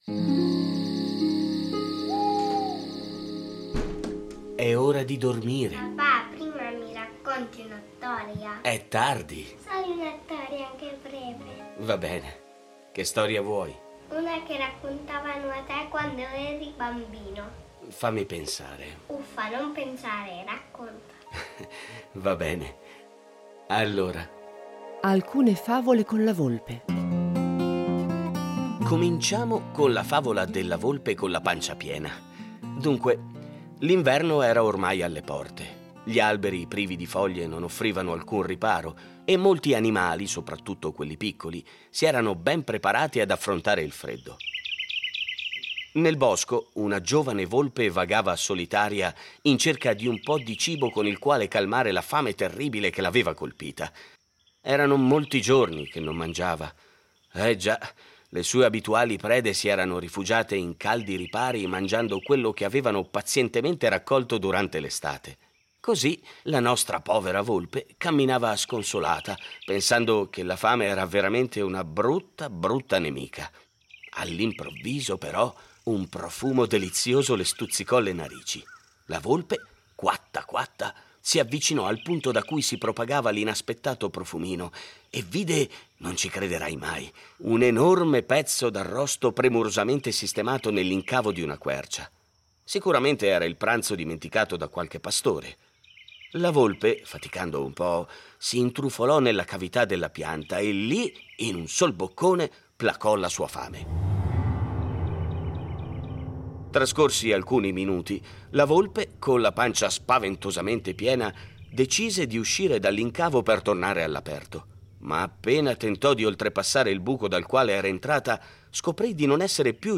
Fiabe
A partire dai testi originali un adattamento radiofonico per far vivere ai bambini storie conosciute, ma un po'dimenticate